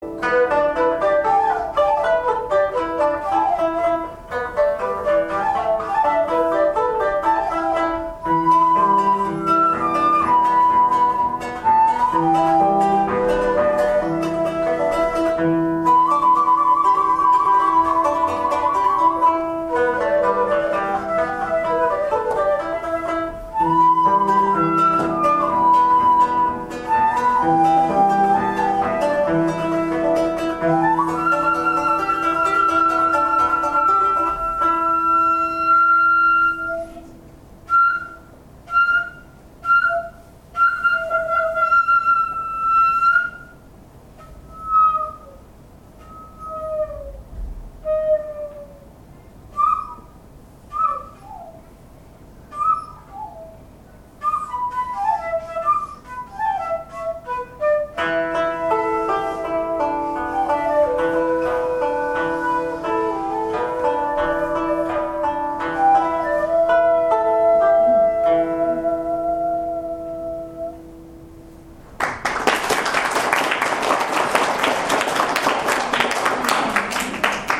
曲の後半は尺八のカデンツ（ソロ）があります。児童生徒への演奏ということできわめて短いソロにしました。
音が出ているのか出ていないのかというぎりぎりの演奏が返って"梢を渡っていく小鳥の雰囲気”がでたみたいです。